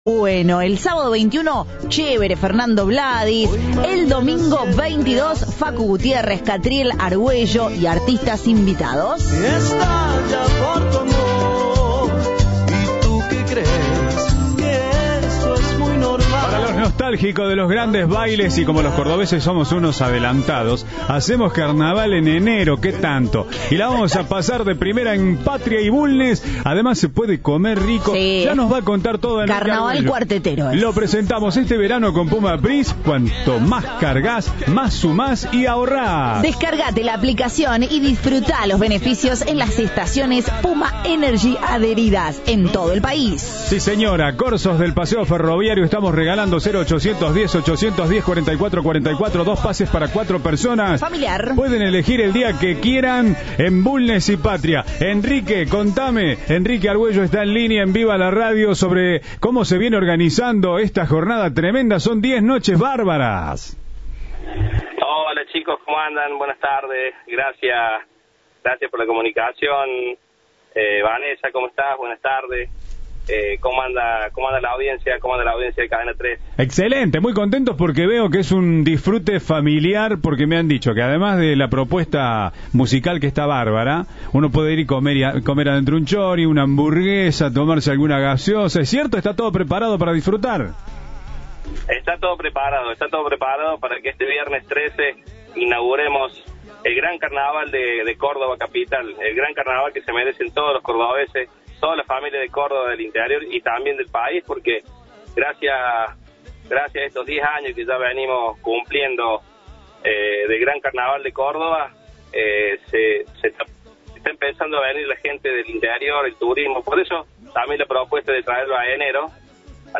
Entrevista de "Viva la Radio".